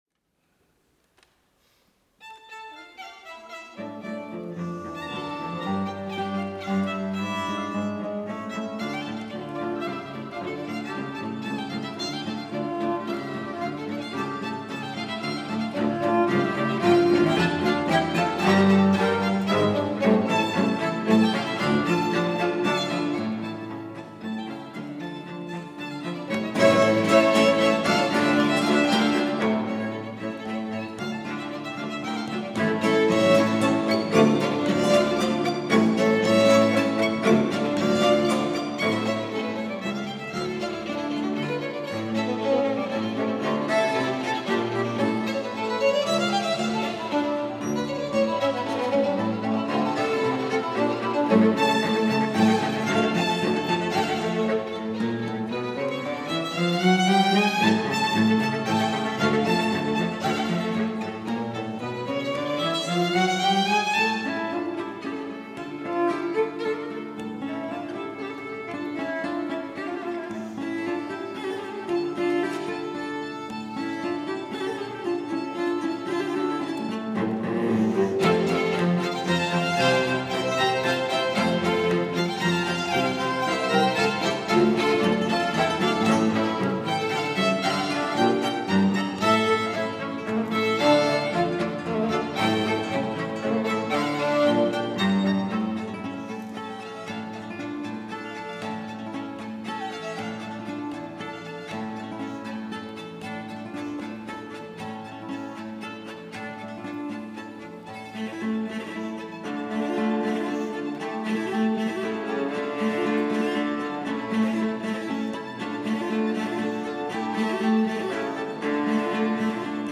Ao estilo galante do século XVIII, encanta pelas suas melodias cheias de charme e emoções tempestuosas, assim como pela variedade de ritmos sincopados típicos das danças espanholas.
Quinteto para guitarra e quarteto de cordas  No. 4 em Ré Maior, "FANDANGO"     (audio